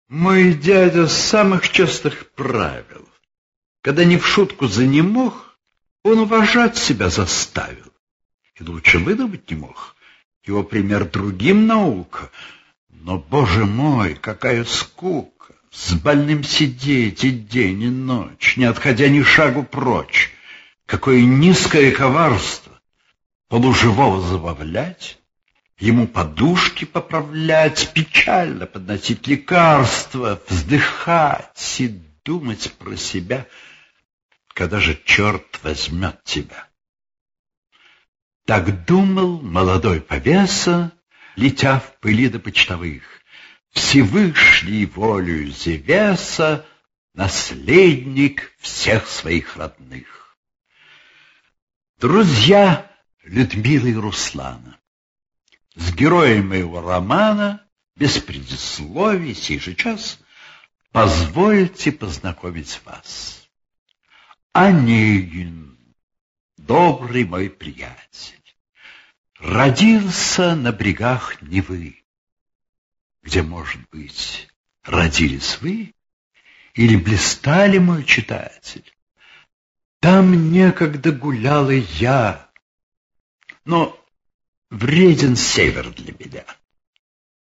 файл) 1,34 Мб Пушкин А.С. "Евгений Онегин" Художественное чтение. 1